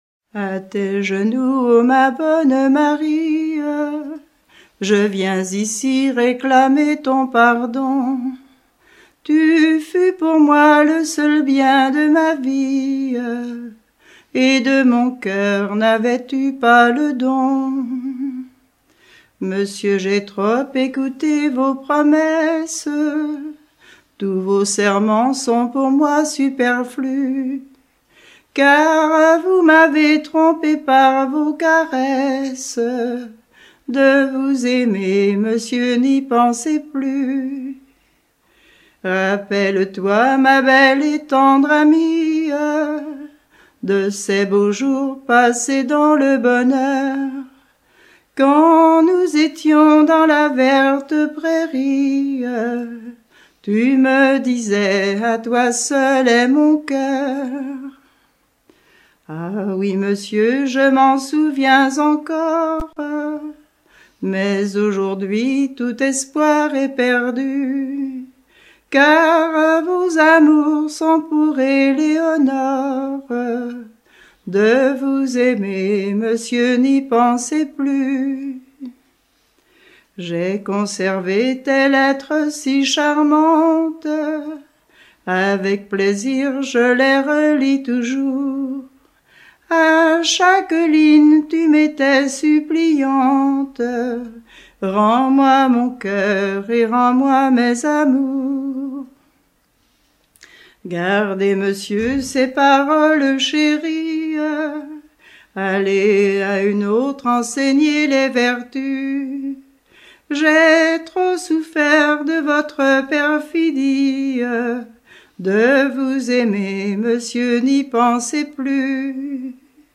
Genre dialogue
Catégorie Pièce musicale éditée